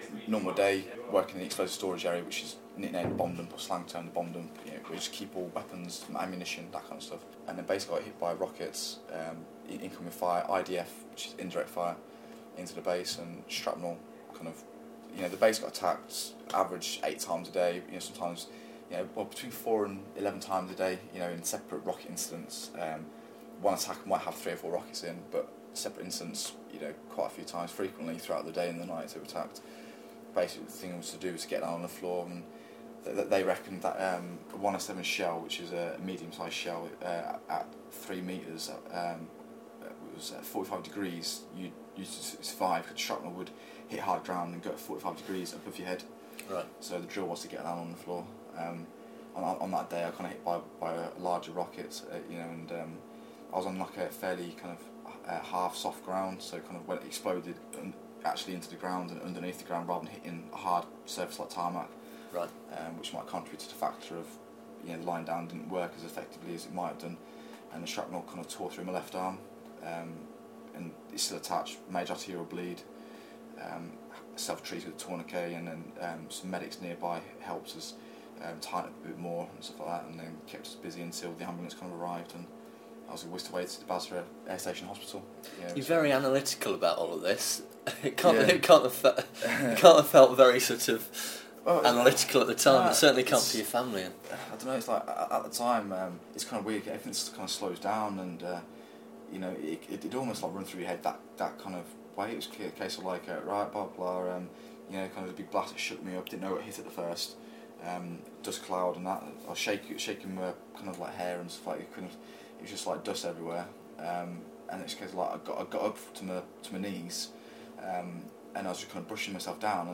Jon-Allan Butterworth describes how he went from route from being a weapons technician in Iraq to a Paralympic track cycling hopeful. Obviously its a frightening story but I found this interview intriguing more because of his matter-of-fact approach and attention to detail - two factors that are a major factor in John-Allan's success.